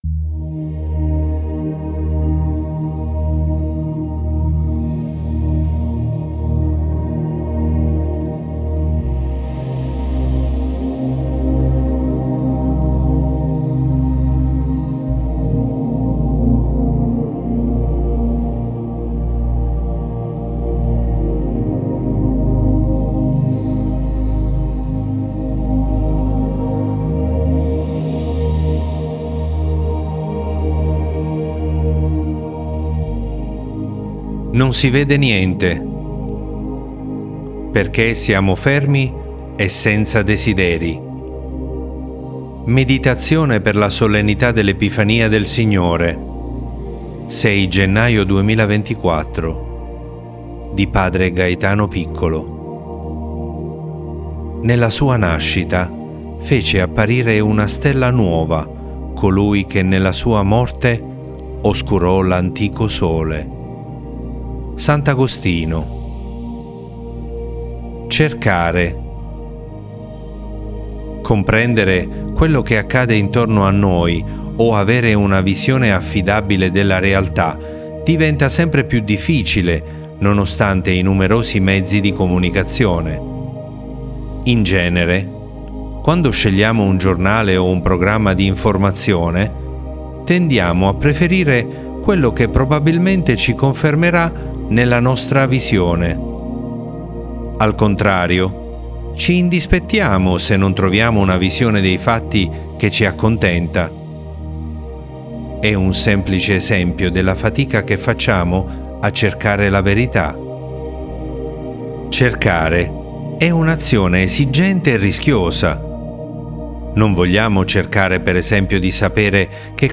Meditazione per la Solennità dell’ Epifania del Signore